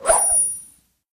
bp_snout_coin_fly_03.ogg